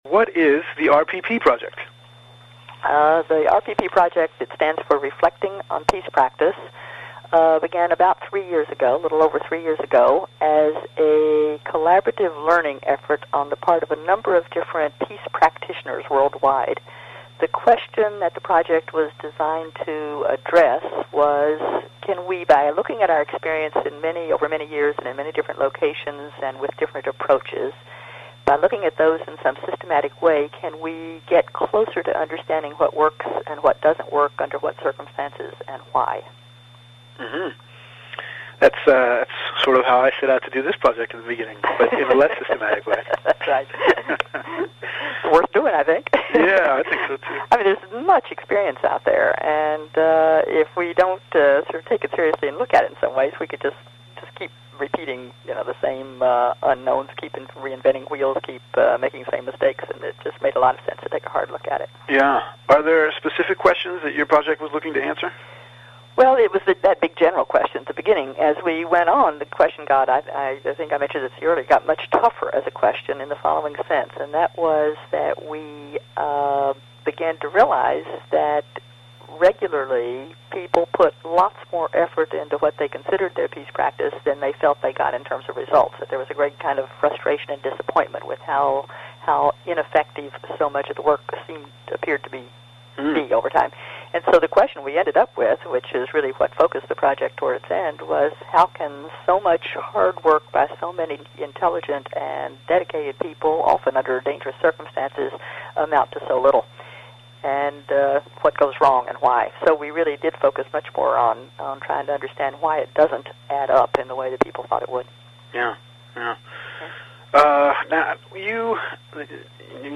Listen/Read Selected Interview Segments on the Following Topics